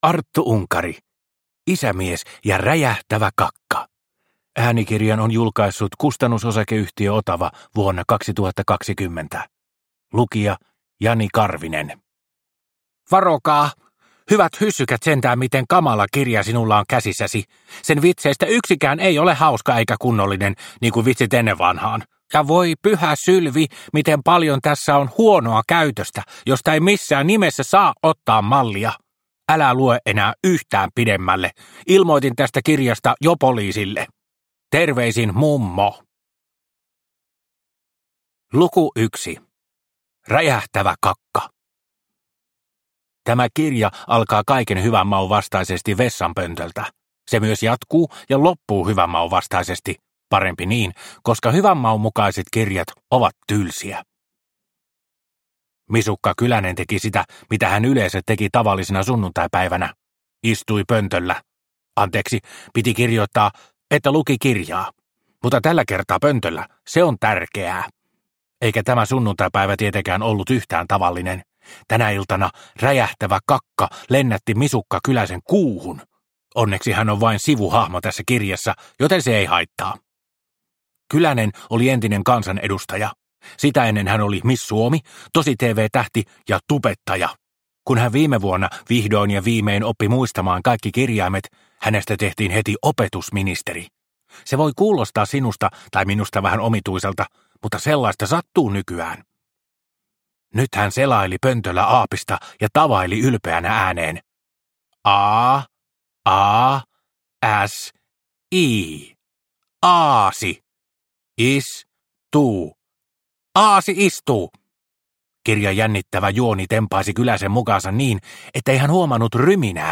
Isämies ja räjähtävä kakka – Ljudbok – Laddas ner